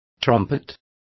Complete with pronunciation of the translation of trumpet.